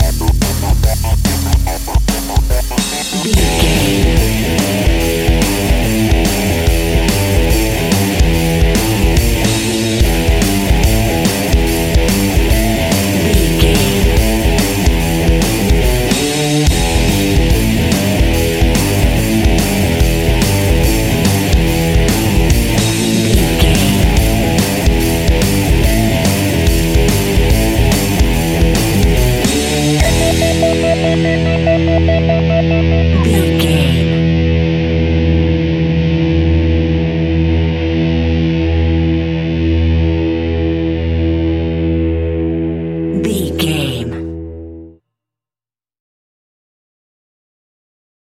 Epic / Action
Aeolian/Minor
heavy metal
blues rock
distortion
instrumentals
rock guitars
Rock Bass
Rock Drums
heavy drums
distorted guitars
hammond organ